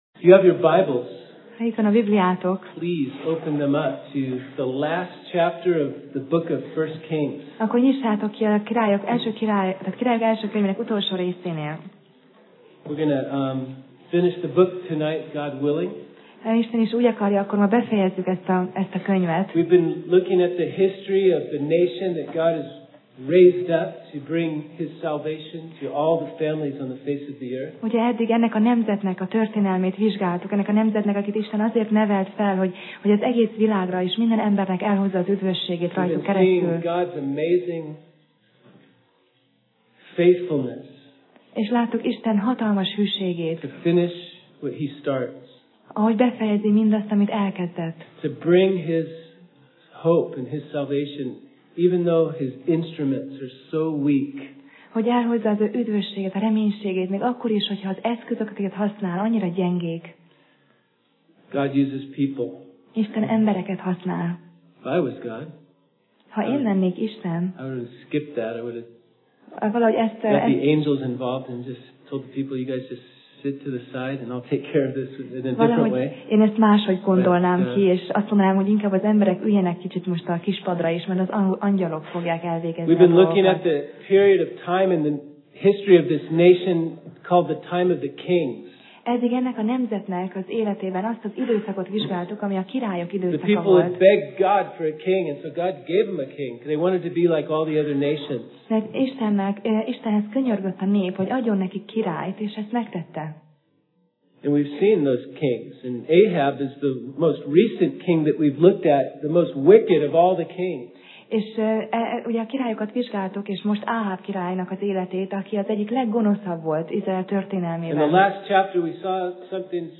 Sorozat: 1Királyok Passage: 1Királyok (1Kings) 22 Alkalom: Szerda Este